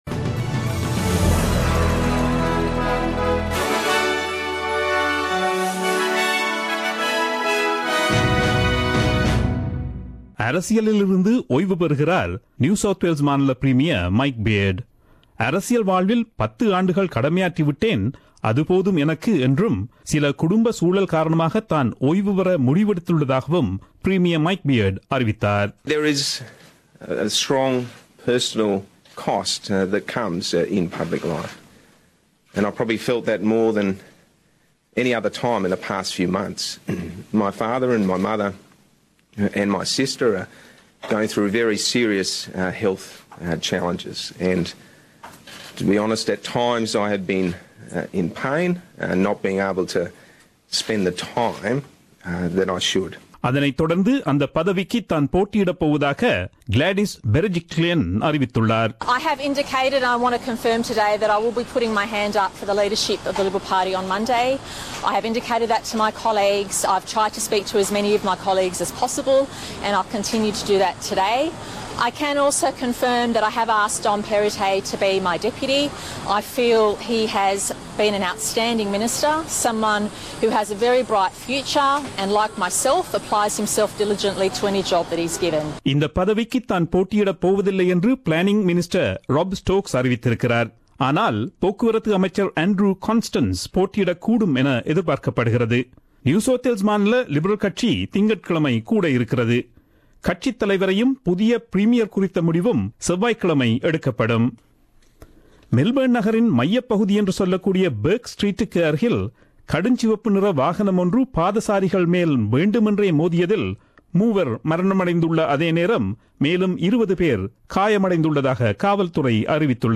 Australian news bulletin aired on Friday 20 Jan 2017 at 8pm.